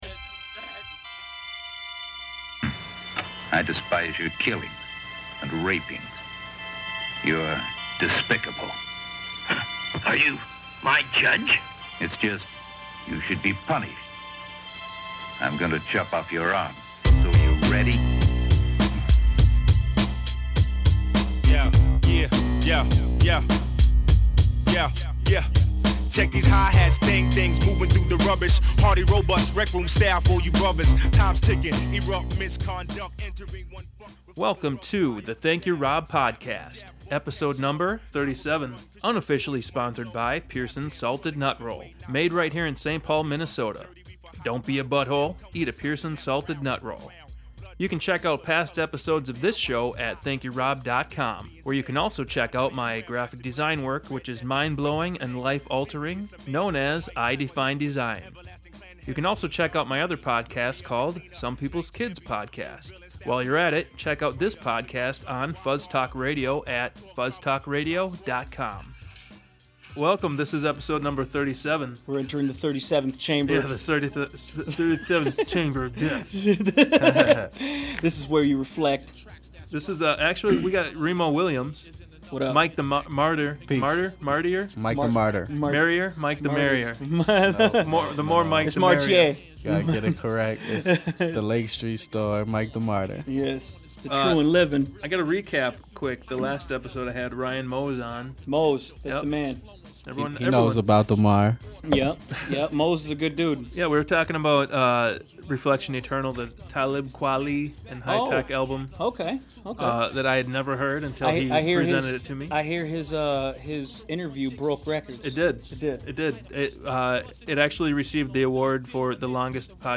This episode was recorded in historic Minneapolis at an undisclosed location.